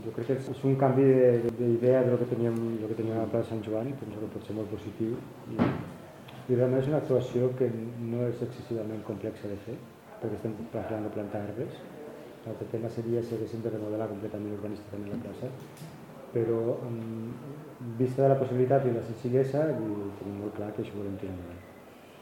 (641.4 KB) Tall de veu del tinent d'alcalde Sergi Talamonte sobre el projecte de plantació d'arbrat a la plaça de Sant Joan.
tall-de-veu-del-tinent-dalcalde-sergi-talamonte-sobre-el-projecte-de-plantacio-darbrat-a-la-placa-de-sant-joan